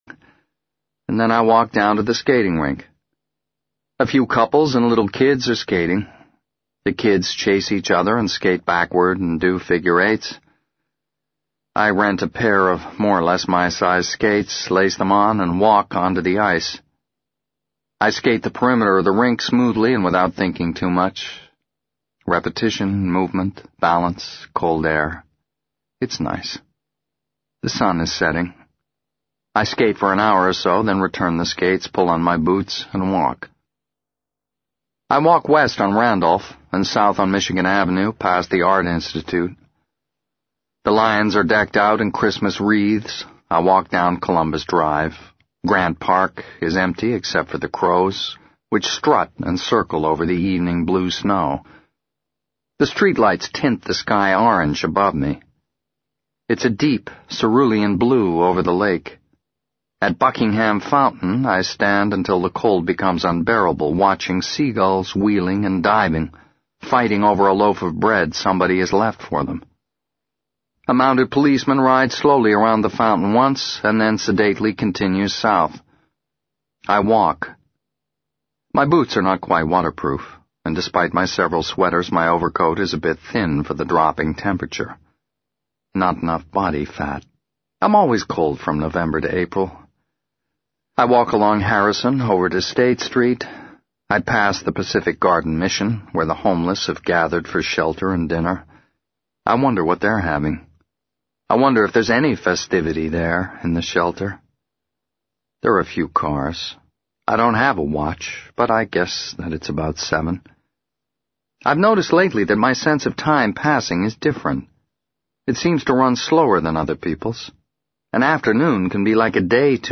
在线英语听力室【时间旅行者的妻子】97的听力文件下载,时间旅行者的妻子—双语有声读物—英语听力—听力教程—在线英语听力室